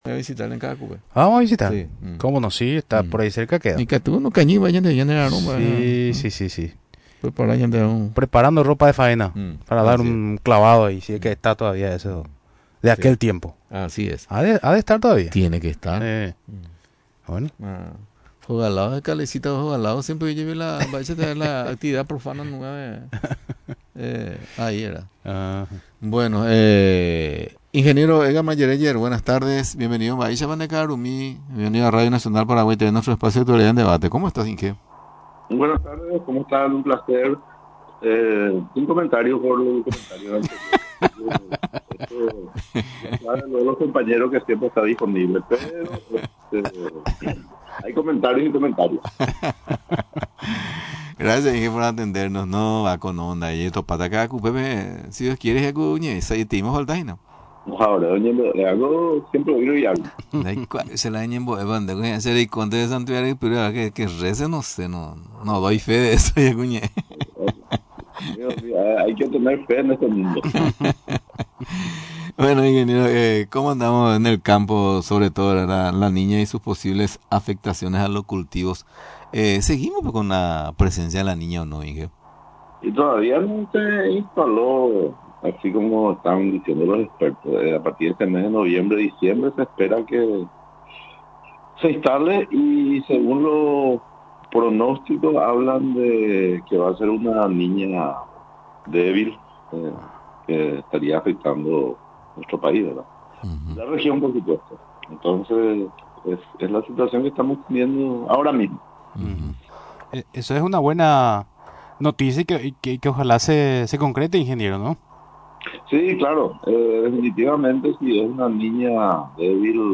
Durante la entrevista en Radio Nacional del Paraguay, expresó que esperan que las lluvias se instalen a partir del mes de diciembre en la zona del Chaco paraguayo.